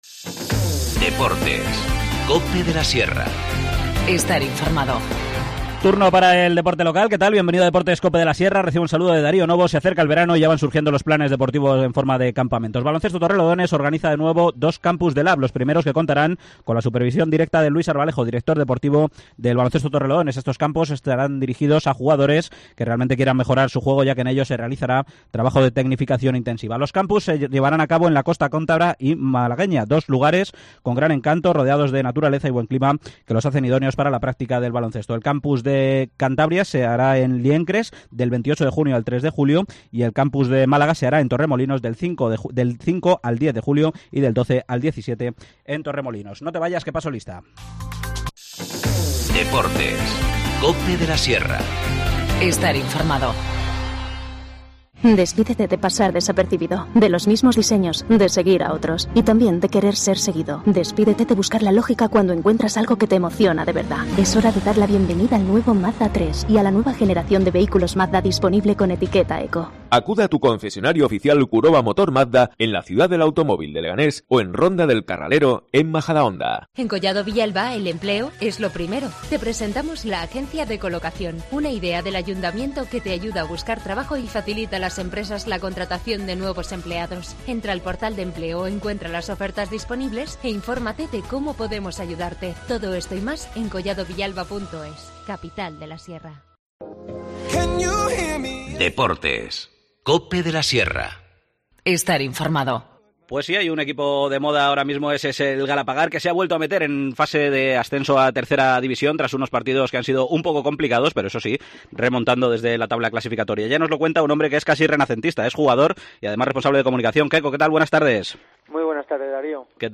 Deportes local